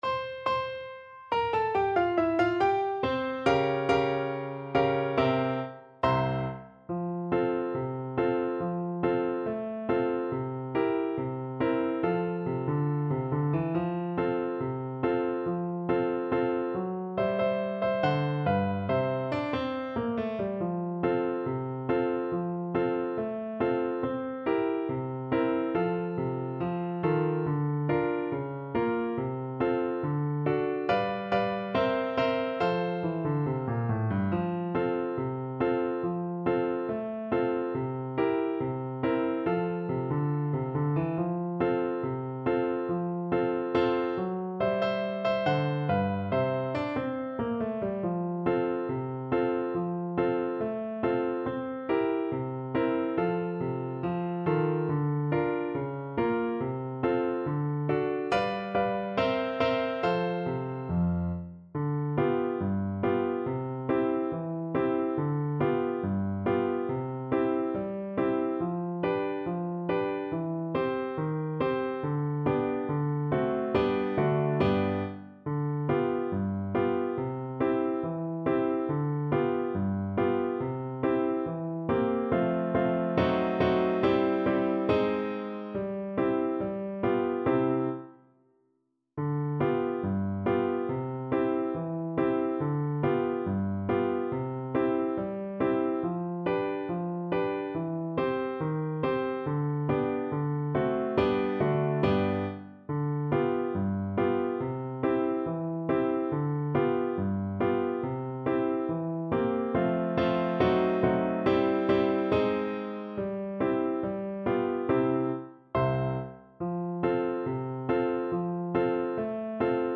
2/4 (View more 2/4 Music)
Not fast Not fast. = 70